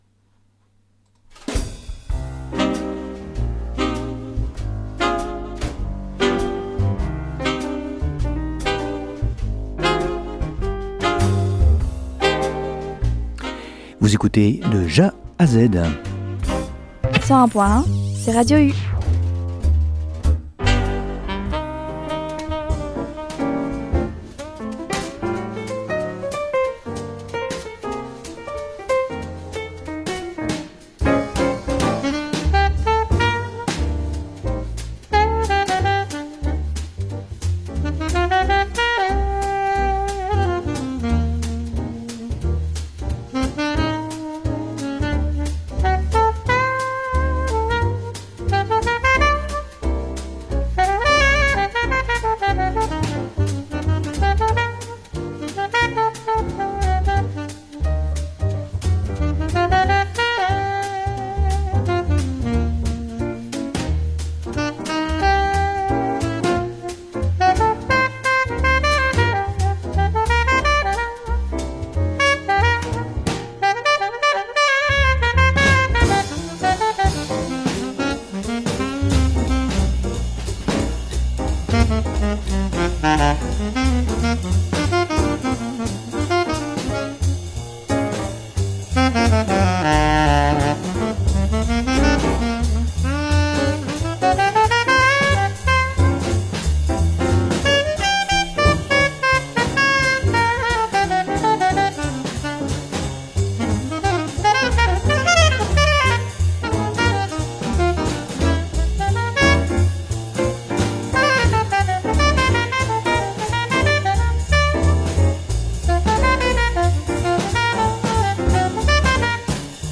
Retrouvez ma sélection Jazz du mois